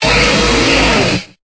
Cri de Rhinastoc dans Pokémon Épée et Bouclier.